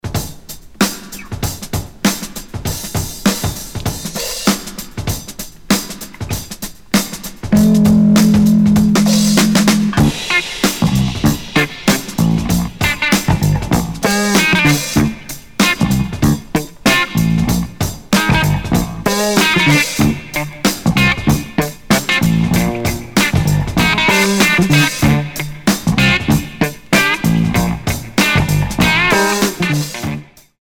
Fat drumbreak intro on the funky
Classic drum kit for hip-hop
Breaks & Loops